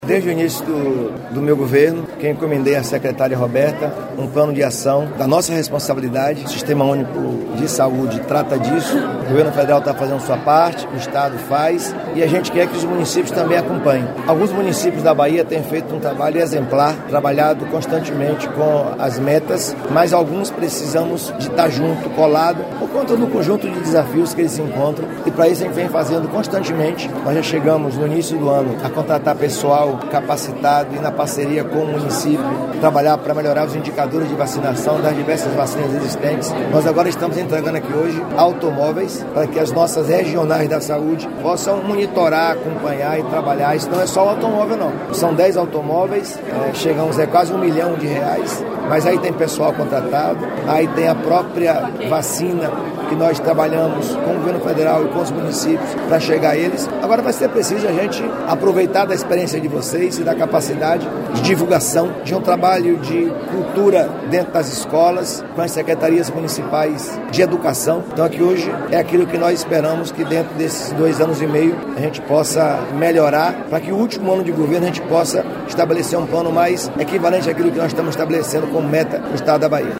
🎙Sonora do Governador da Bahia Jerônimo Rodrigues